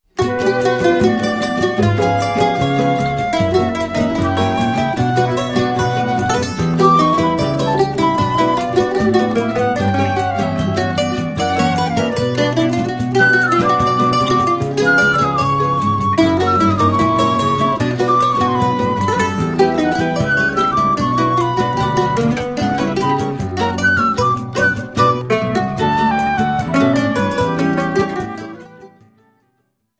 mandolin
flute